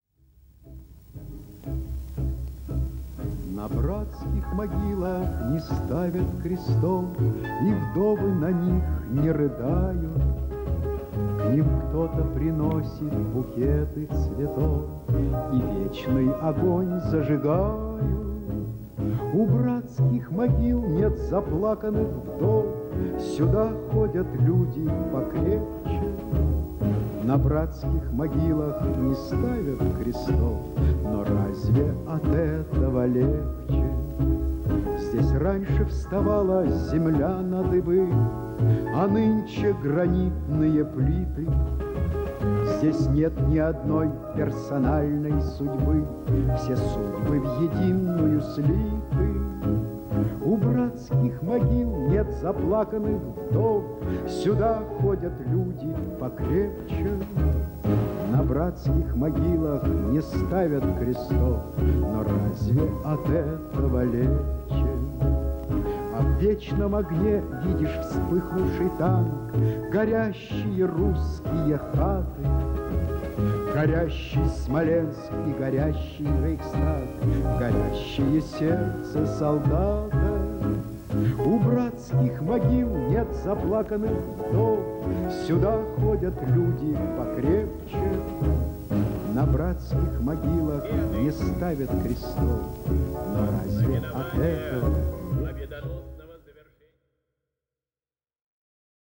Скажу сразу - в подборке качество очень различное.